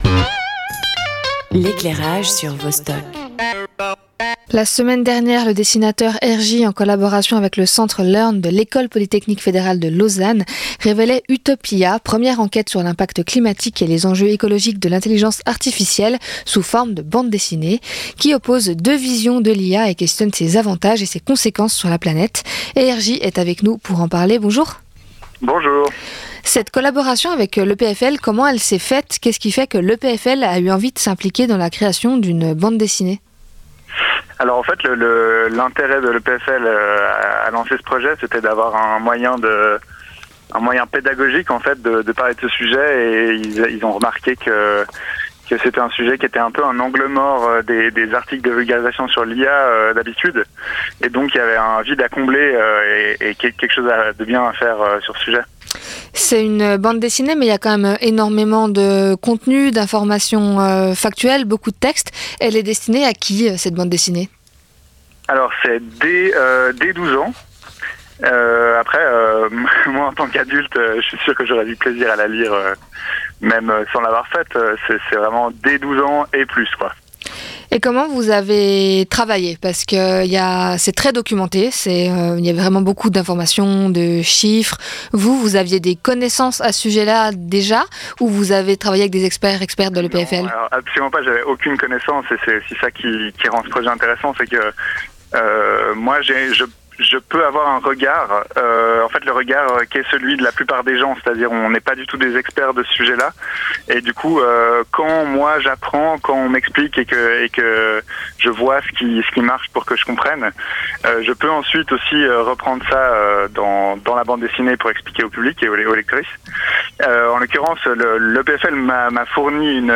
Première diffusion antenne : 27 mai 2025